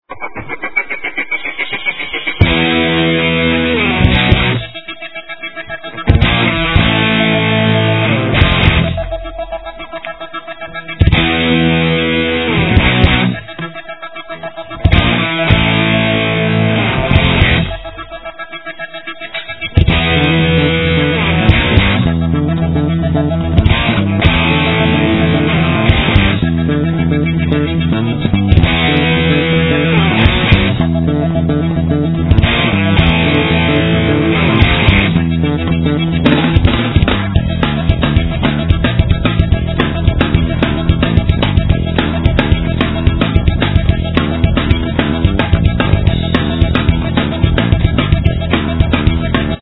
Guitar
Drums
Sax
Bass